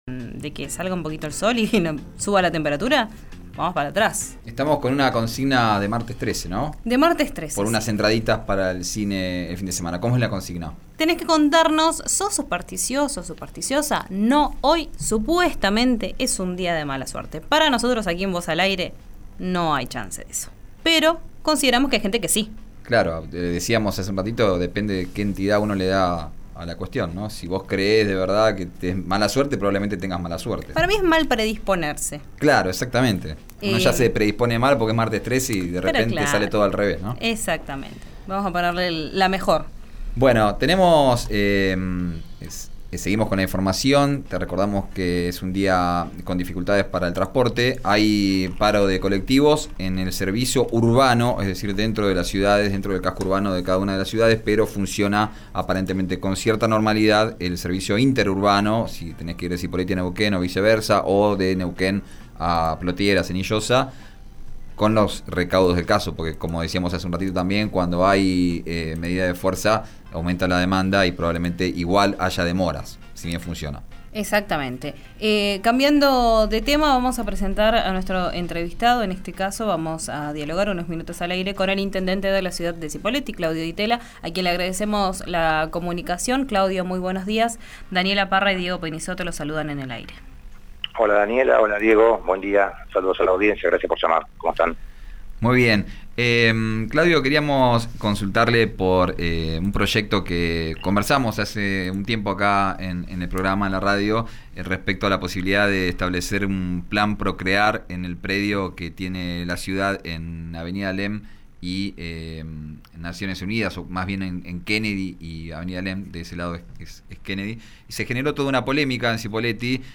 El colegio de martilleros y el de arquitectos manifestaron su descontento frente al proyecto. Di Tella habló al respecto en RÍO NEGRO RADIO.